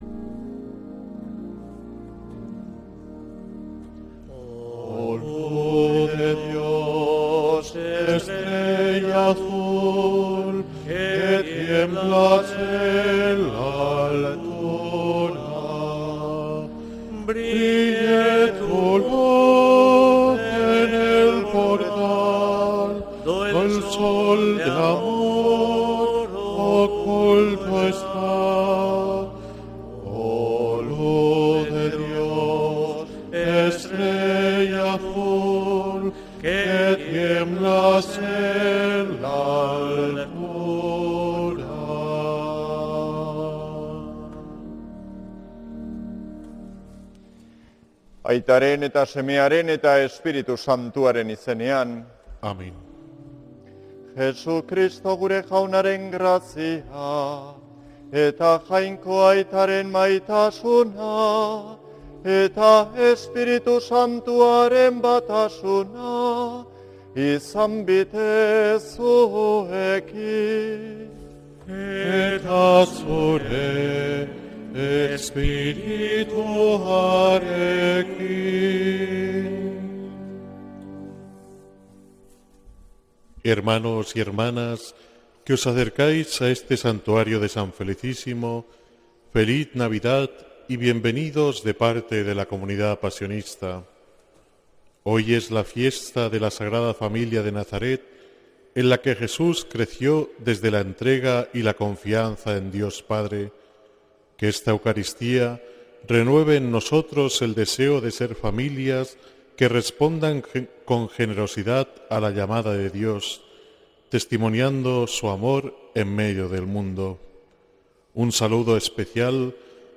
Santa Misa desde San Felicísimo en Deusto, domingo 28 de diciembre de 2025